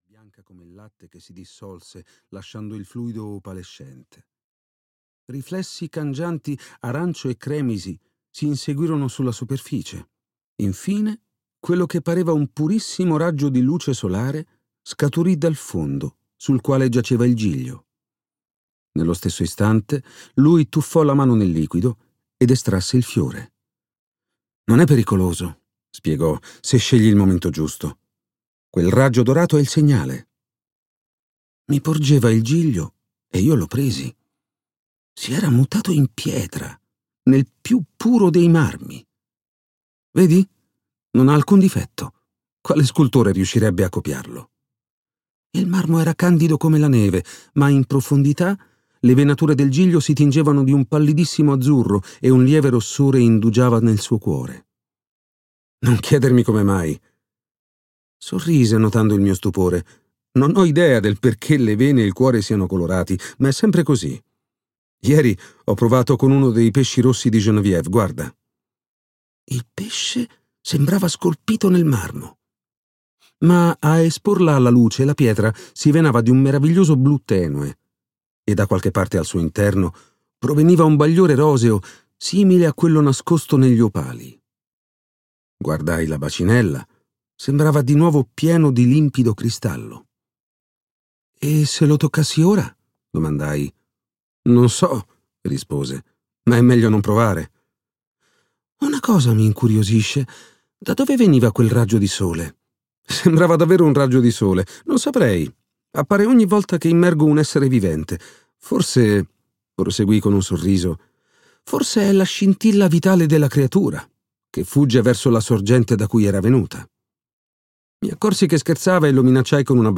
"Il Re Giallo" di Robert W. Chambers - Audiolibro digitale - AUDIOLIBRI LIQUIDI - Il Libraio